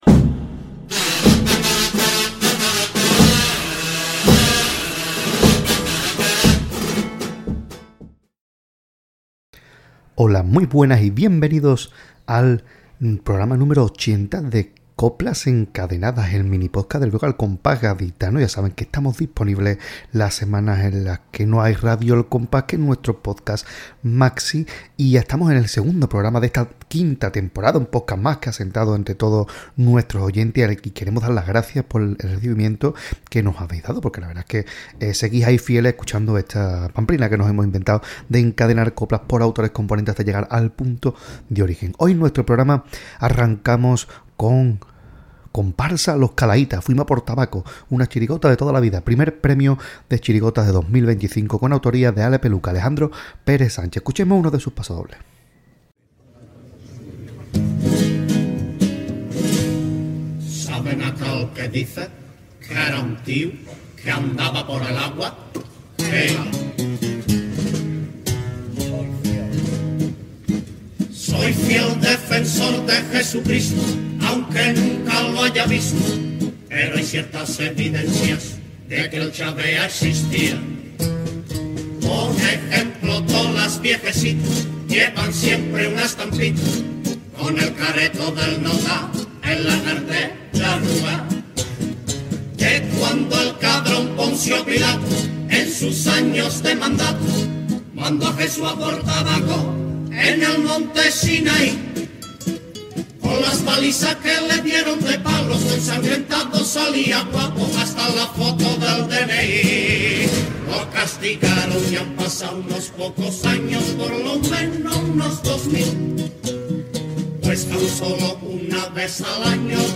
Pasodoble